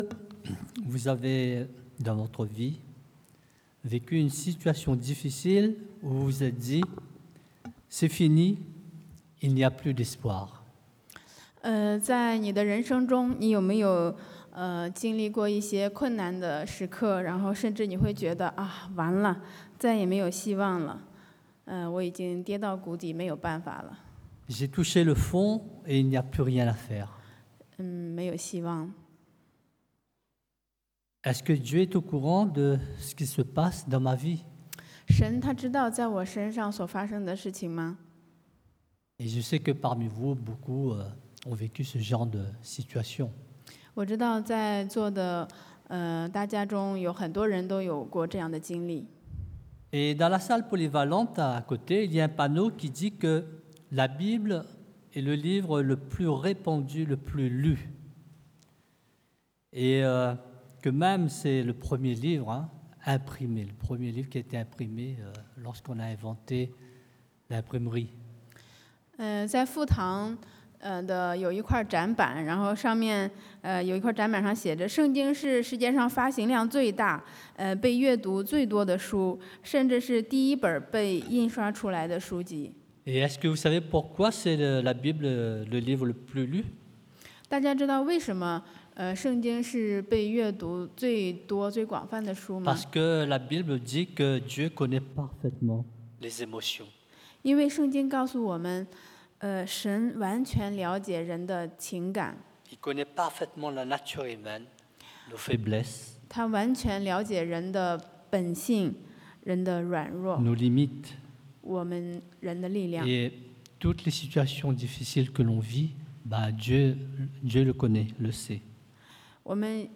Passage: Ezéchiel 以西結書 37 :1-14 Type De Service: Predication du dimanche « Vois ou ne vois pas ?